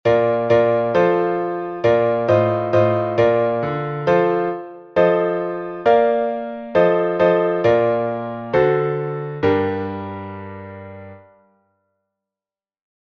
Глас 7